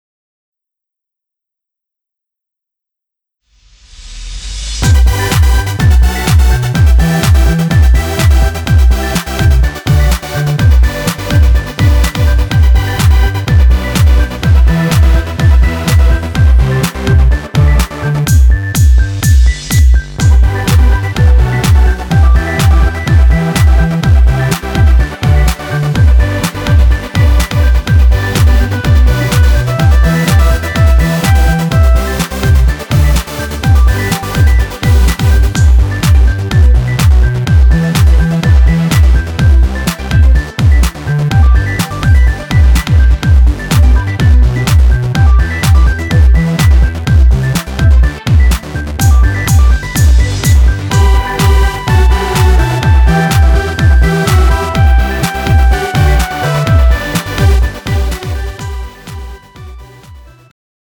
음정 여자-1키
장르 축가 구분 Pro MR